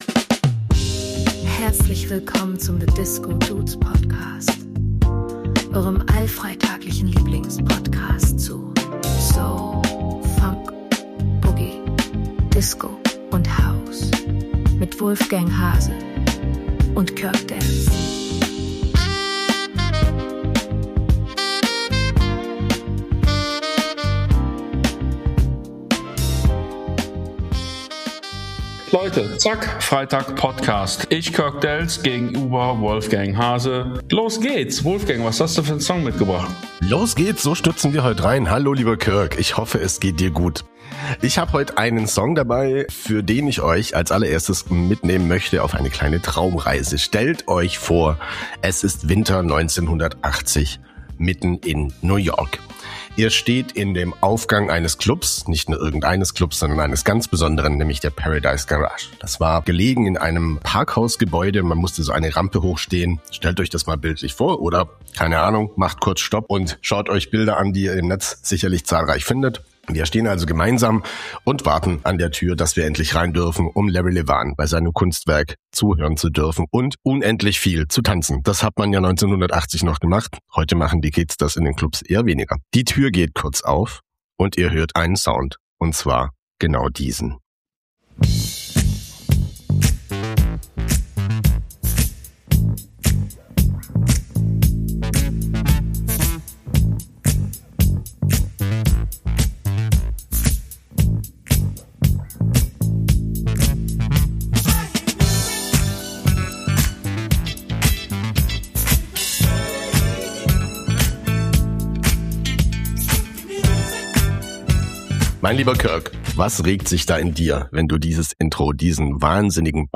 Listening-Talk-Session zwischen Paradise-Garage-Vibe, Boogie-Klassiker und Funk-Deep-Cut.